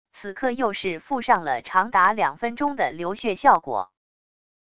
此刻又是附上了长达两分钟的流血效果wav音频